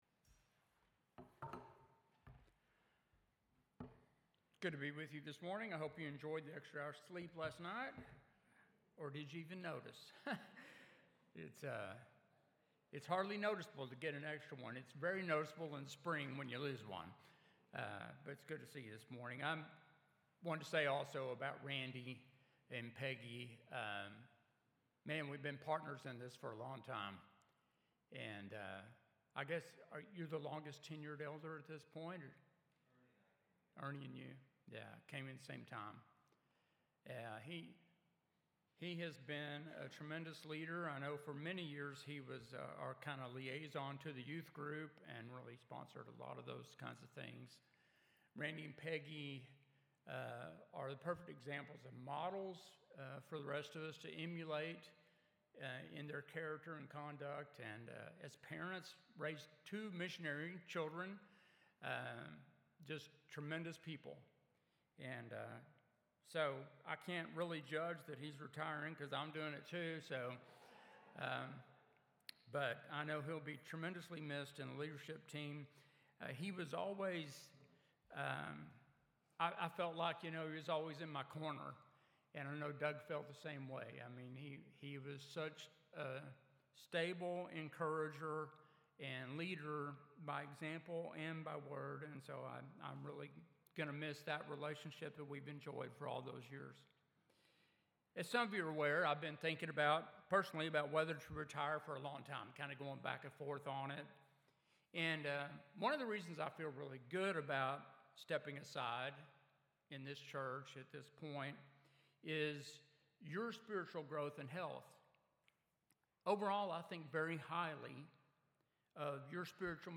Sermons | Westport Road Church of Christ